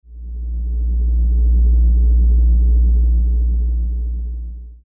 lift.mp3.svn-base